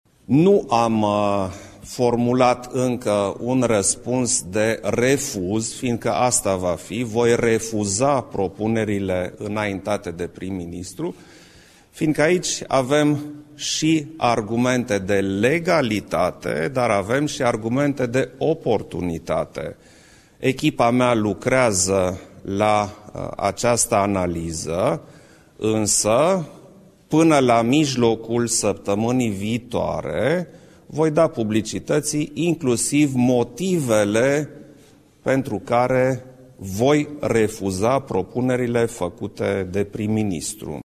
Într-o conferință de presă la Palatul Cotroceni, șeful statului a mai declarat că nu îl va revoca din funcție pe procurorul general, Augustin Lazăr.